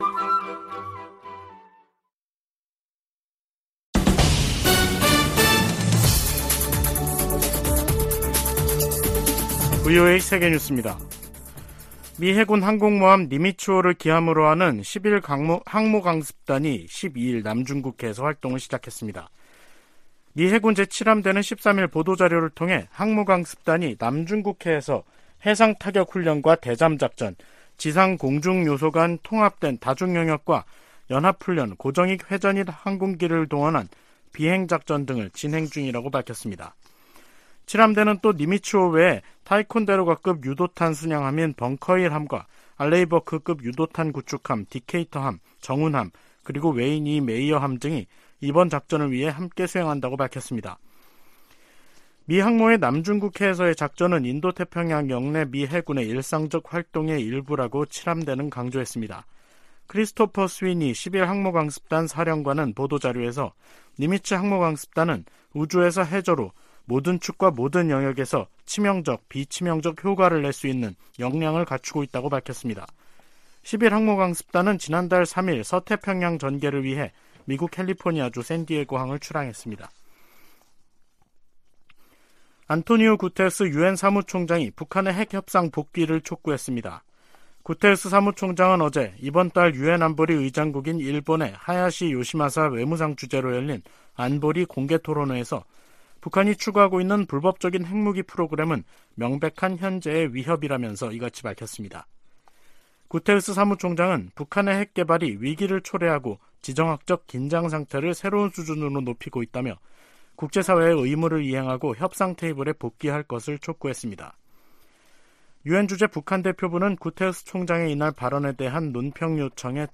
VOA 한국어 간판 뉴스 프로그램 '뉴스 투데이', 2023년 1월 13일 3부 방송입니다. 미 국무부는 미국과 한국, 일본이 북한의 핵과 탄도미사일 프로그램을 심각한 위협으로 받아들이고 있으며, 이를 막기 위해 3자 차원의 대응을 강화하고 있다고 밝혔습니다. 미국 백악관은 윤석열 한국 대통령의 자체 핵보유 언급과 관련해 한반도의 완전한 비핵화 입장에 변함이 없다고 강조했습니다.